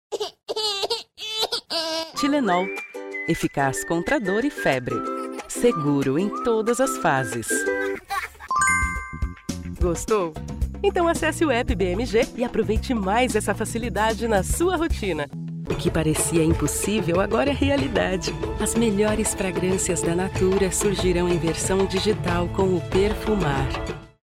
Crível
Enérgico
Esquentar